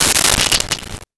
Camera Flash Bulb Crackle 03
Camera Flash Bulb Crackle 03.wav